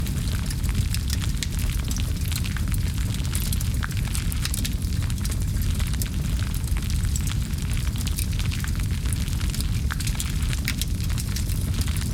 Fire_Loop_02.wav